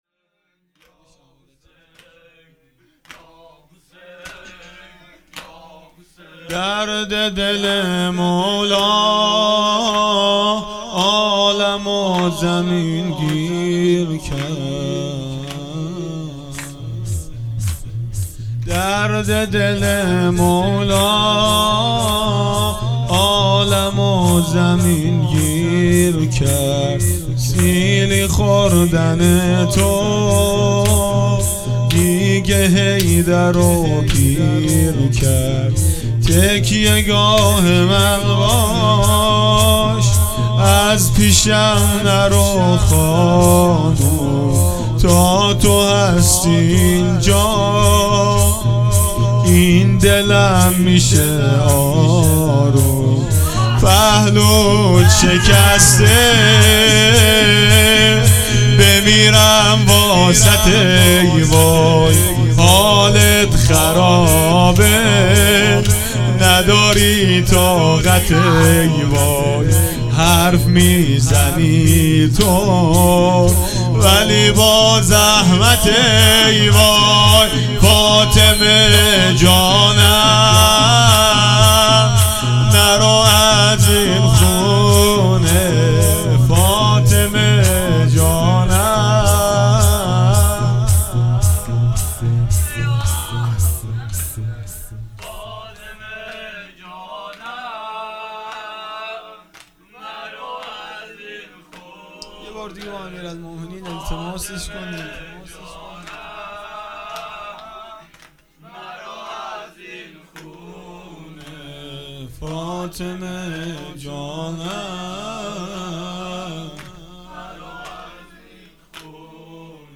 وفات حضرت ام البنین ۱۷-۱۱-۹۸